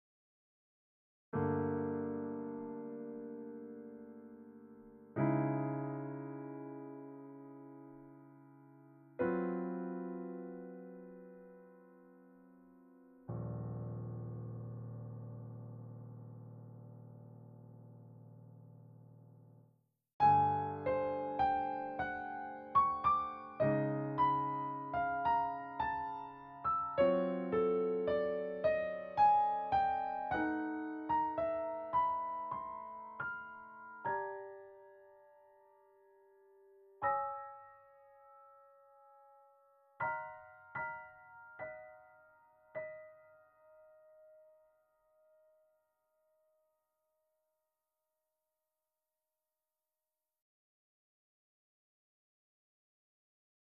piano Duration